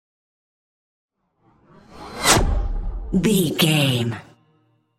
Pass by fast speed flash
Sound Effects
Fast
futuristic
pass by
vehicle